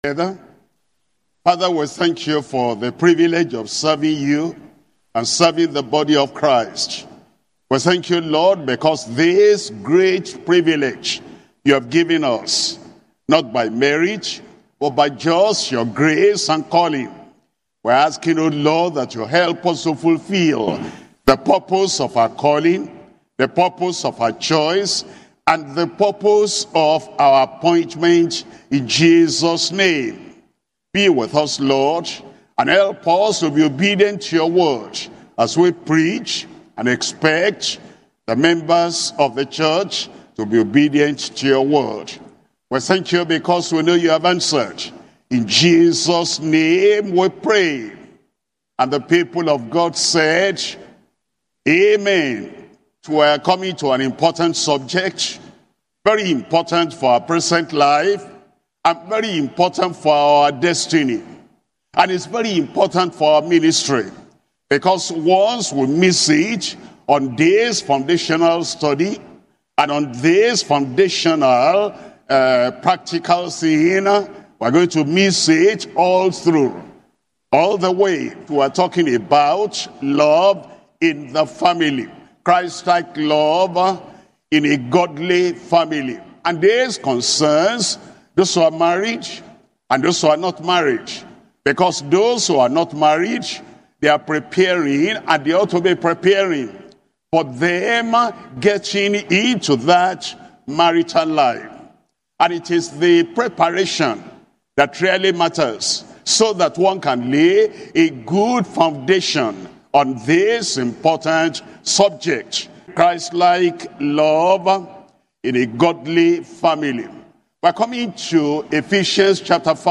Sermons - Deeper Christian Life Ministry
2025 Global Family and Marriage Conference